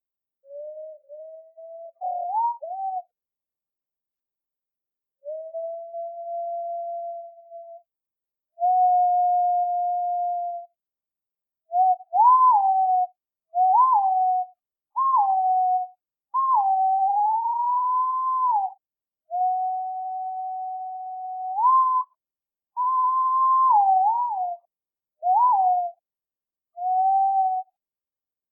アオバト｜日本の鳥百科｜サントリーの愛鳥活動
「日本の鳥百科」アオバトの紹介です（鳴き声あり）。